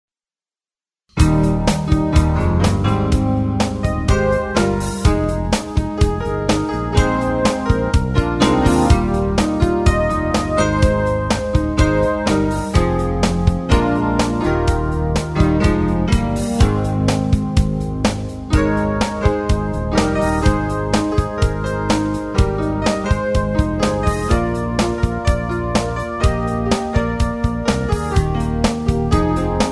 Vocal and Instrumental MP3 Tracks
Instrumental Tracks.